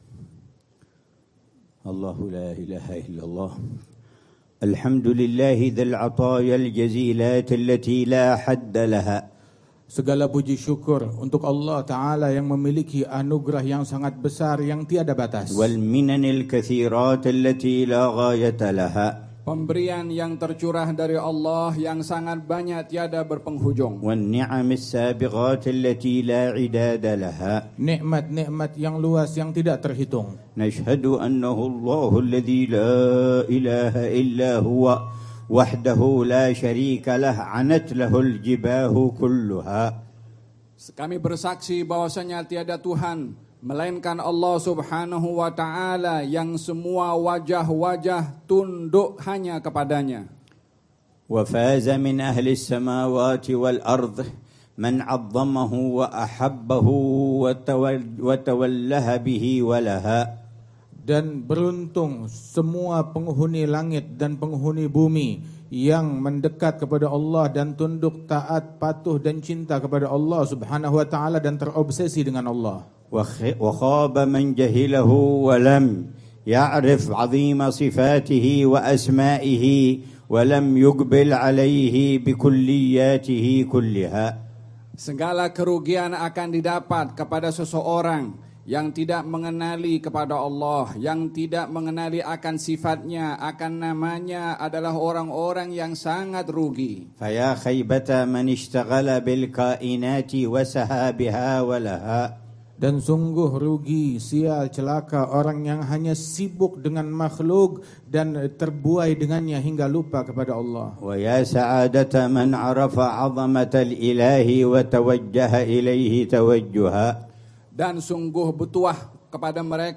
محاضرة في المجلس الكبير في باهانج، ماليزيا 1447هـ
محاضرة للعلامة الحبيب عمر بن محمد بن حفيظ، في مجلس الوعظ والتذكير الكبير في ولاية باهانج، ماليزيا، ليلة الخميس 24 ربيع الثاني 1447هـ